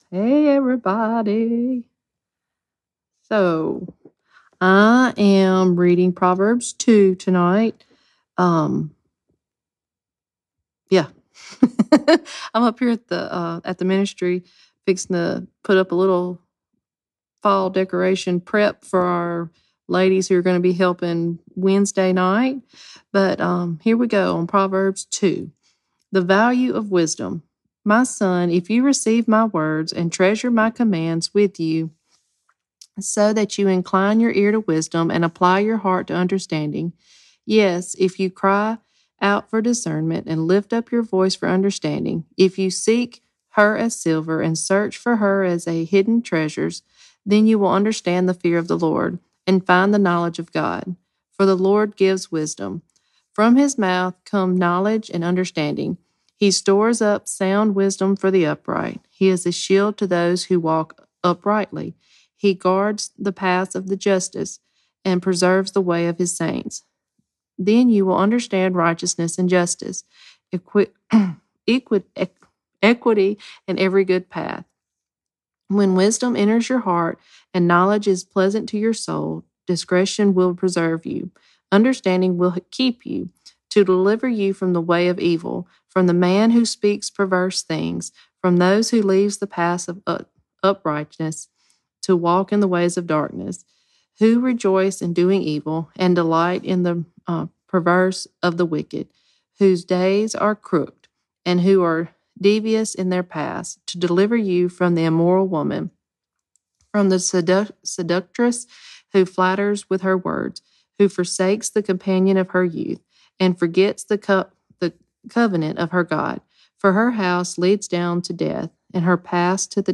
Proverbs Community Reading